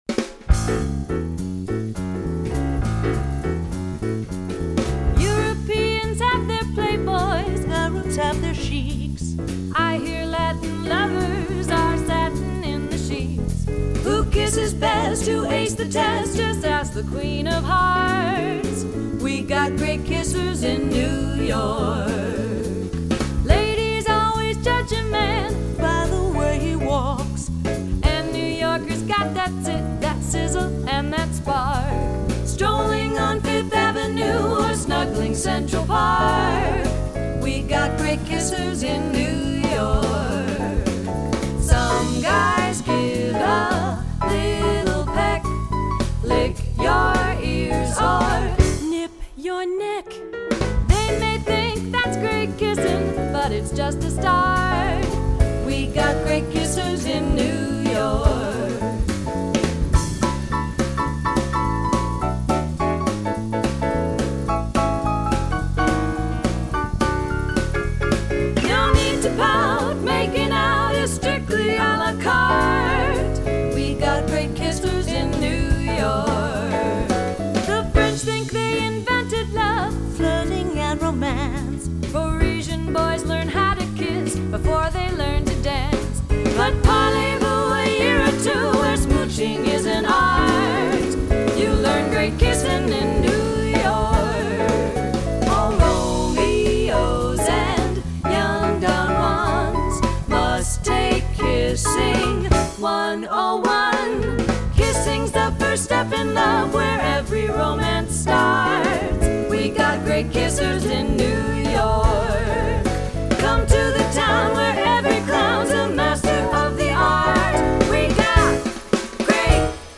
Hop aboard the F Train for this grand, old-school, Broadway-style musical comedy being presented for one performance only in a concert reading at Catskill’s Bridge Street Theatre on Sunday May 21 at 2:00pm.